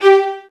VIOLINS.AN-R.wav